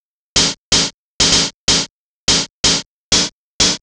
cch_percussion_loop_chordhat_125.wav